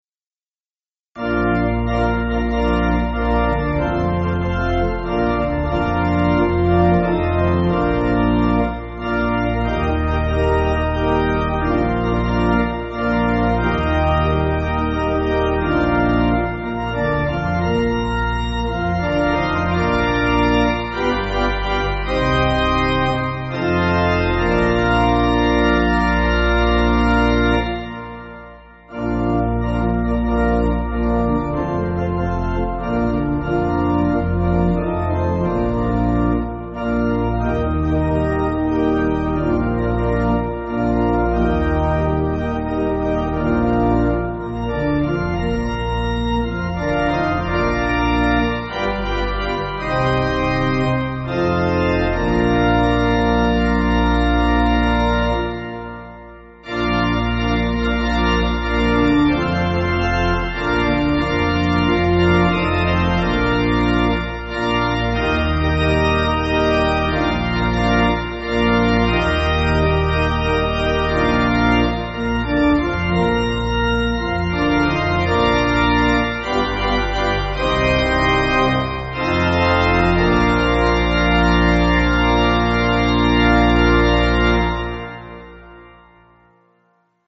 (CM)   3/Bb